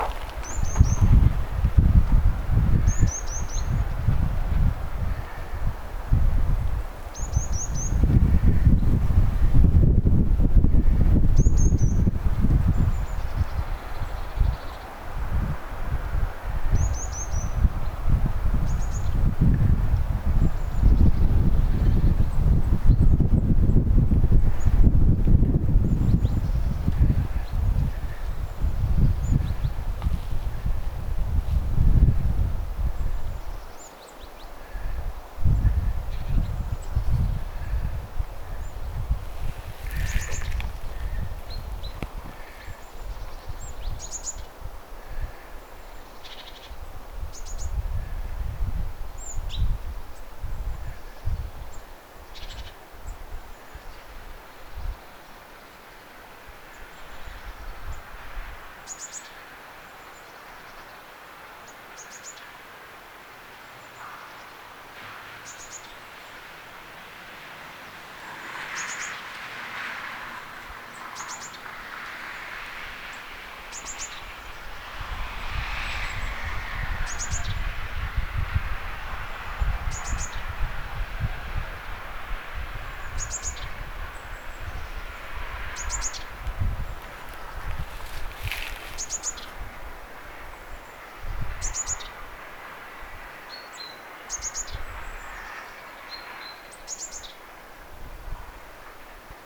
sinitiainen ja talitiainen
että selvä muutos tiaisten ääntelystä
Voitko bongata talitiaiselta vielä
titityy-laulua?
sinitiainen_ja_talitiainen.mp3